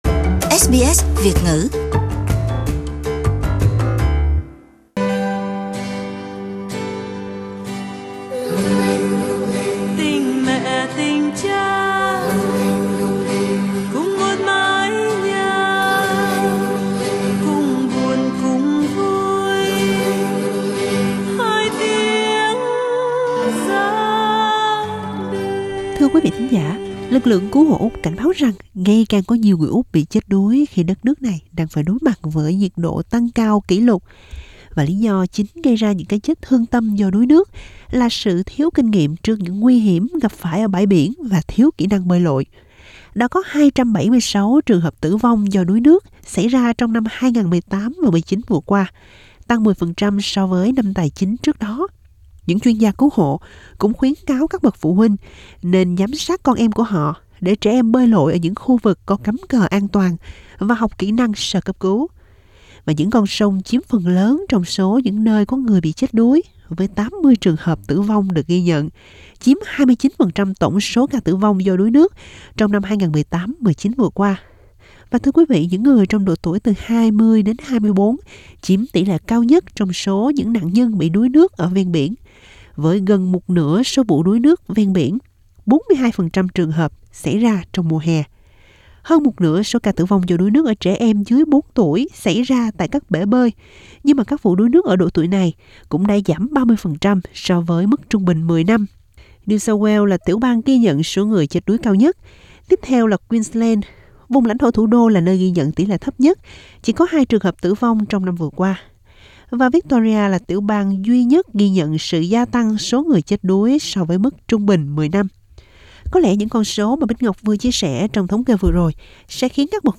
Một ông bố Việt chia sẻ về lớp học bơi cho trẻ sơ sinh tại miền Tây Melbourne.